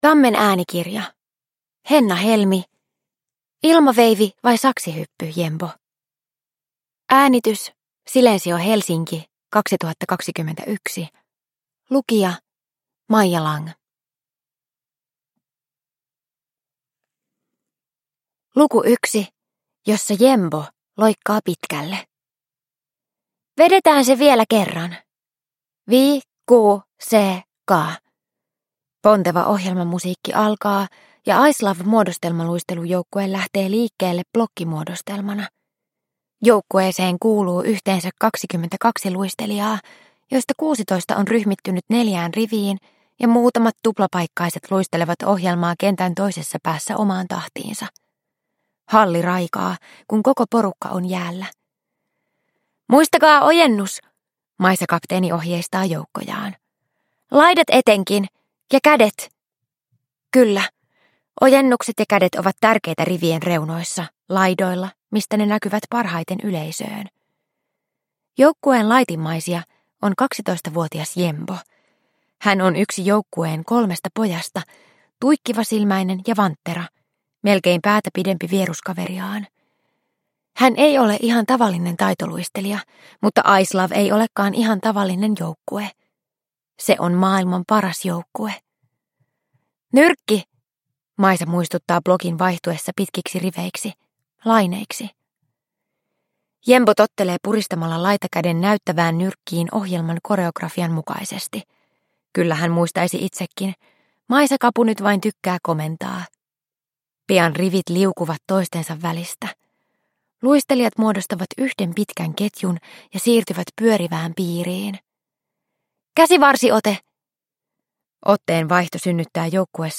Ilmaveivi vai saksihyppy, Jembo? – Ljudbok